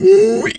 pgs/Assets/Audio/Animal_Impersonations/pig_2_hog_single_03.wav at master
pig_2_hog_single_03.wav